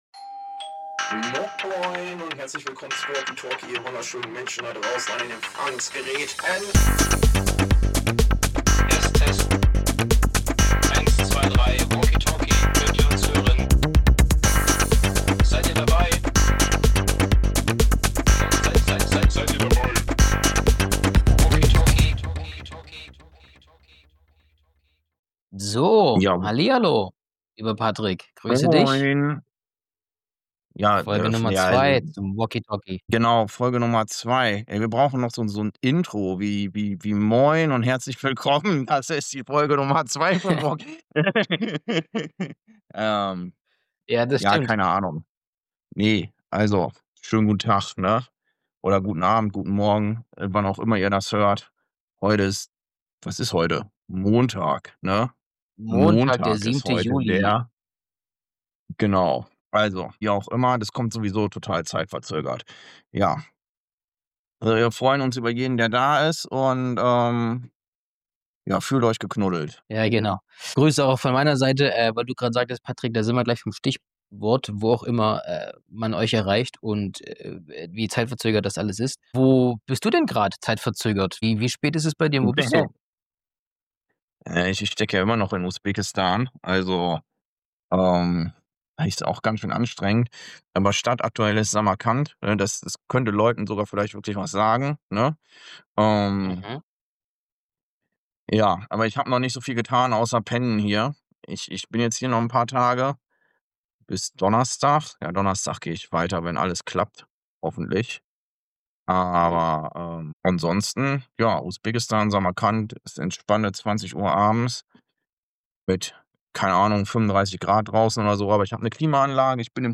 Beschreibung vor 7 Monaten In dieser Episode diskutieren die Gastgeber ihre Reiseerfahrungen, insbesondere in Usbekistan und Tschechien. Sie reflektieren über die Unterschiede in der Gastfreundschaft zwischen Deutschland und anderen Ländern, die Herausforderungen beim Reisen und die Flexibilität, die das Reisen mit einem Rucksack mit sich bringt.